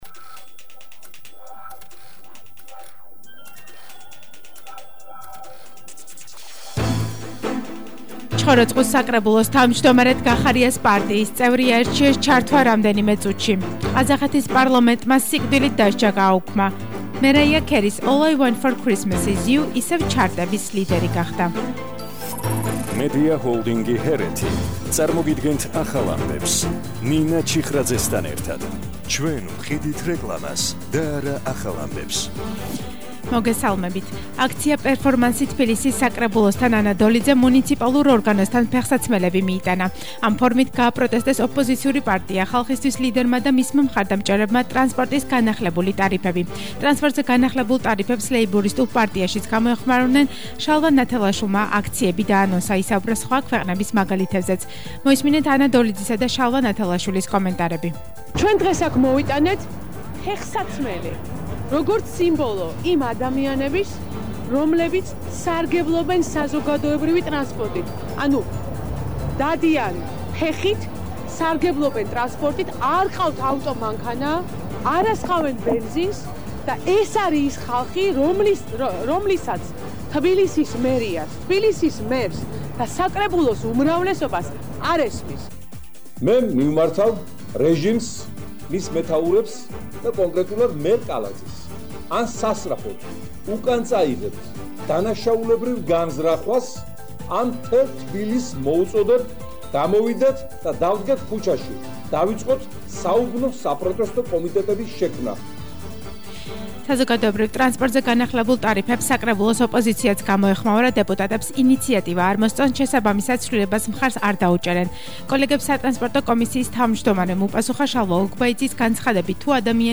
ახალი ამბები 17:00 საათზე – 23/12/21 – HeretiFM